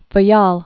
(fə-yäl, fä-)